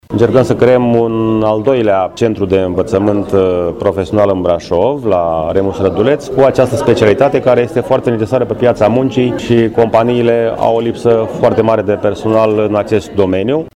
Primarul Brașovului, George Scripcaru: